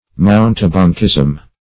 Search Result for " mountebankism" : The Collaborative International Dictionary of English v.0.48: Mountebankism \Mount"e*bank*ism\, n. The practices of a mountebank; mountebankery.